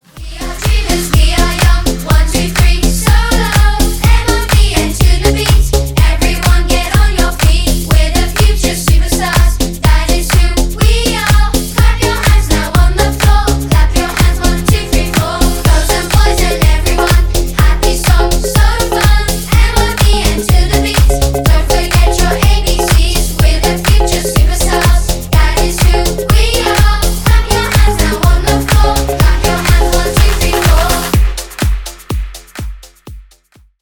Поп Музыка # Танцевальные
весёлые